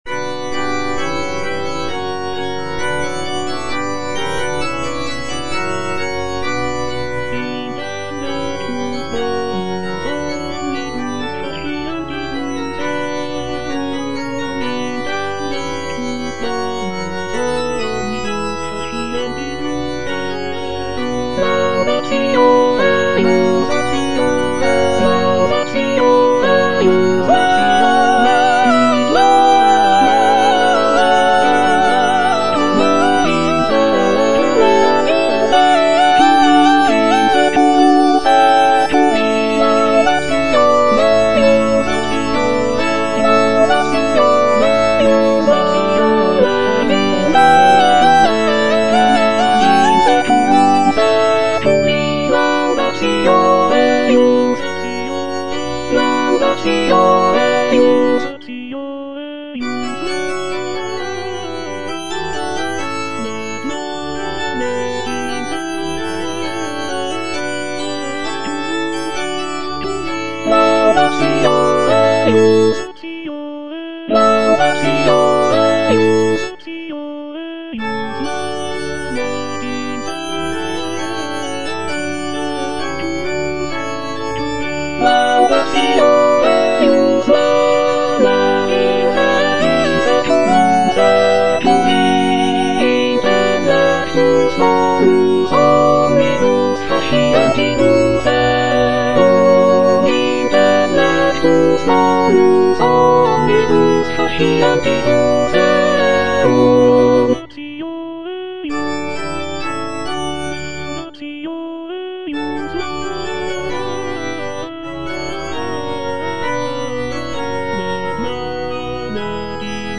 M.R. DE LALANDE - CONFITEBOR TIBI DOMINE Intellectus bonus omnibus - Soprano (Emphasised voice and other voices) Ads stop: auto-stop
"Confitebor tibi Domine" is a sacred choral work composed by Michel-Richard de Lalande in the late 17th century.